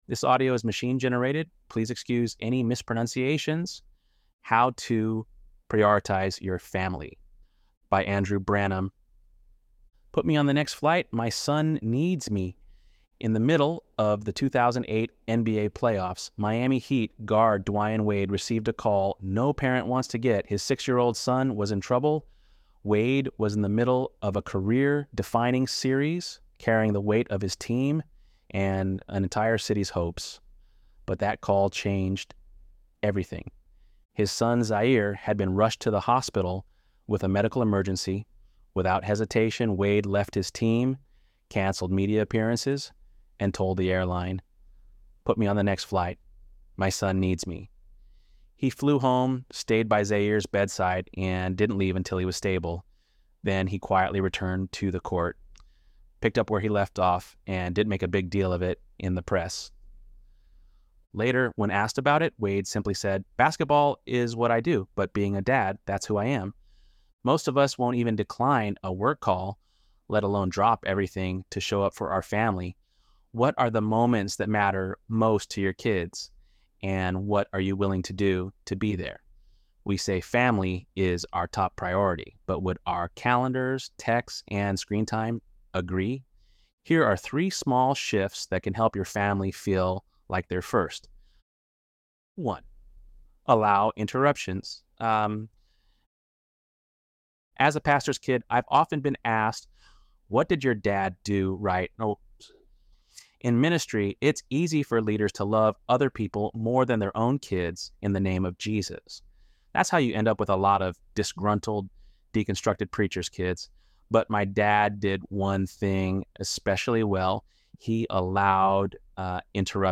ElevenLabs_8.1_Priority.mp3